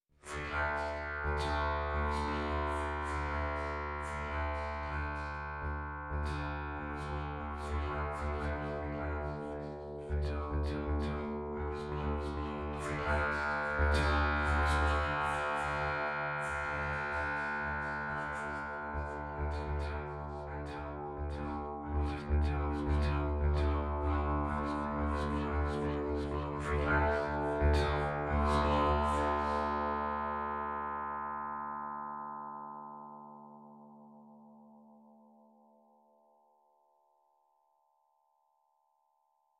or by processing it with a multi-stage Karplus-Strong string resonator.
Audio Example: Voice processed with string resonator
08_Loop_string-resonator.mp3